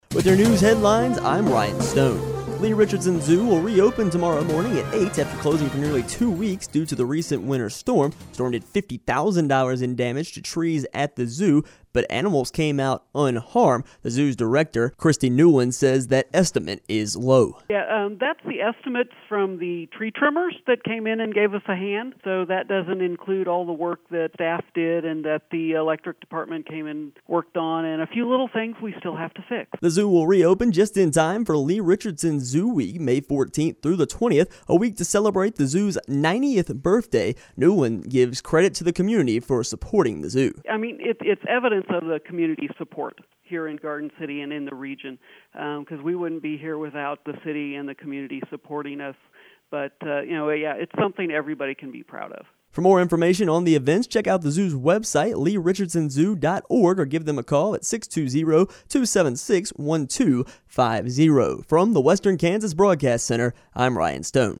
Radio Story